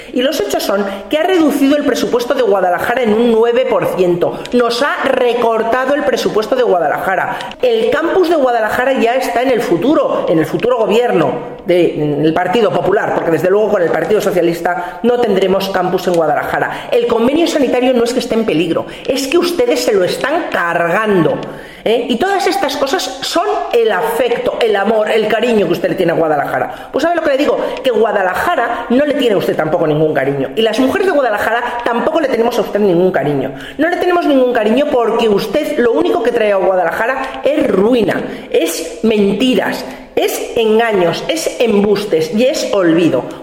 La diputada nacional del PP, Silvia Valmaña, ha afirmado hoy en rueda de prensa que Page no perdona a Guadalajara que esta provincia hiciera caer el régimen socialista en Castilla-La Mancha, un régimen basado en el miedo, en el silencio y aquello de que el que se mueva no sale en la foto.